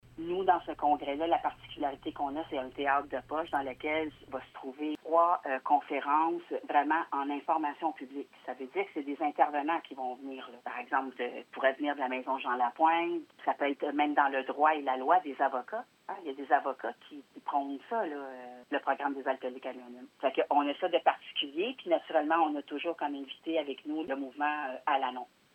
une des membres du comité organisateur du congrès nous parle de quelques particularités.